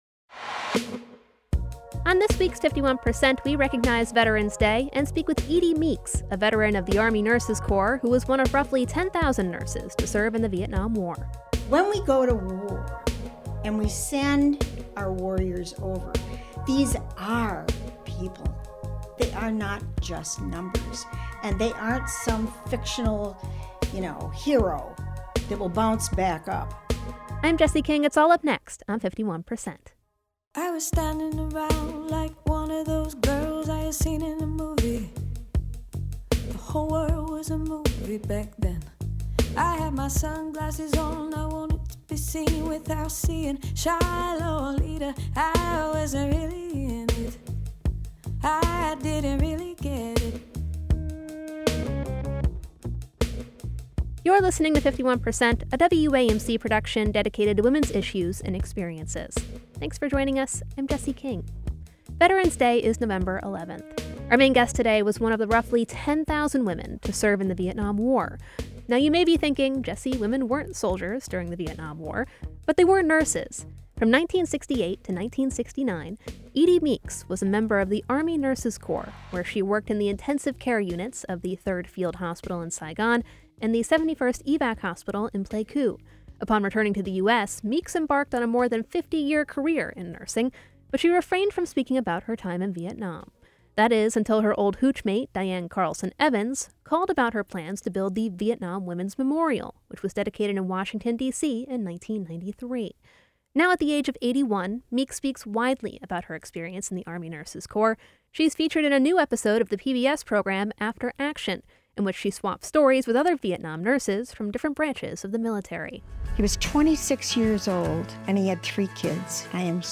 veteran of the Army Nurses Corp featured on PBS' After Action 51% is a national production of WAMC Northeast Public Radio in Albany, New York.